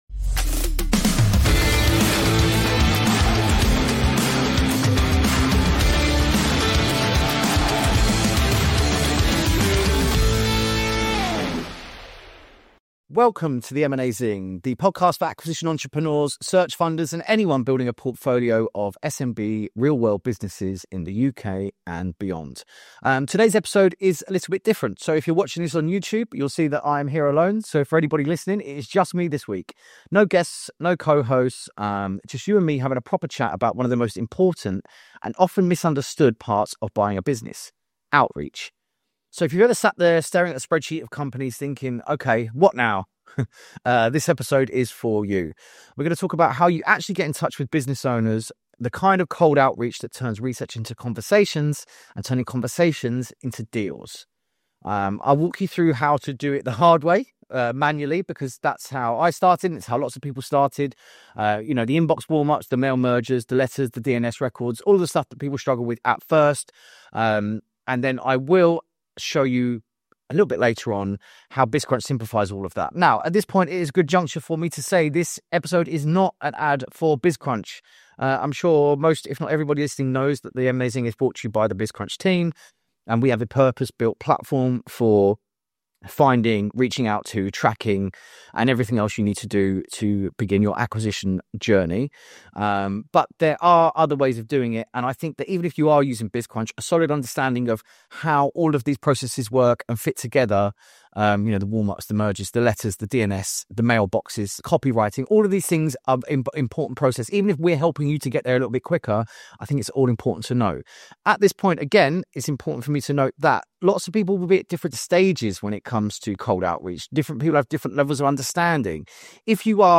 In this special solo edition of M&A Zing